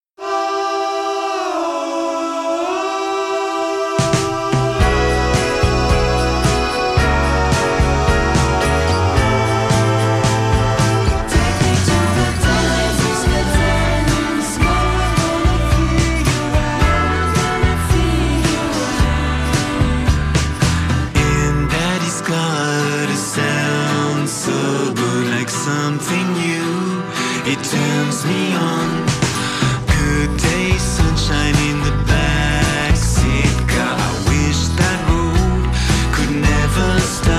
geïnspireerd op de Beatles